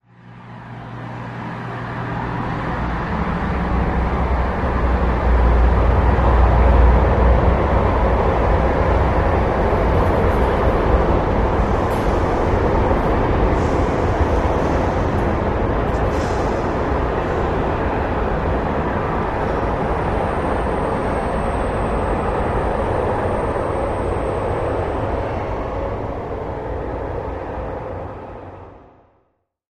Traffic Pass distant doppler shifts, gradual fade